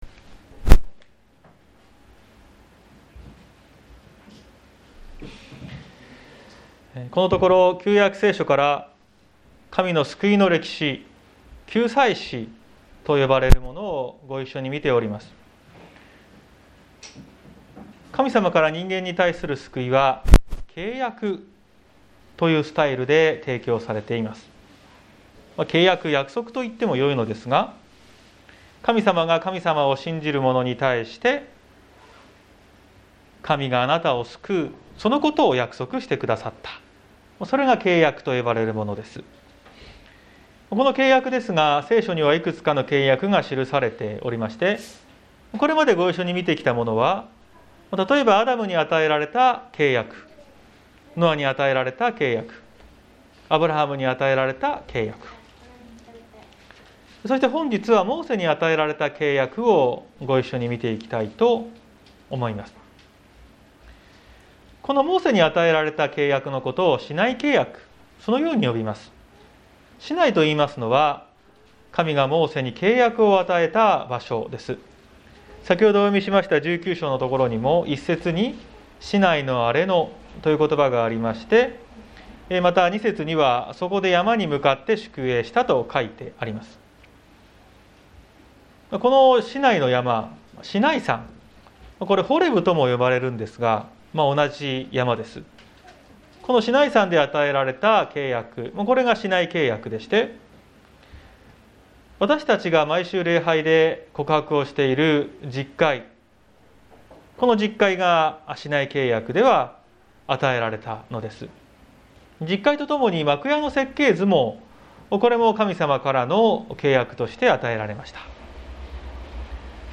2021年11月14日朝の礼拝「神の宝として生きる」綱島教会
綱島教会。説教アーカイブ。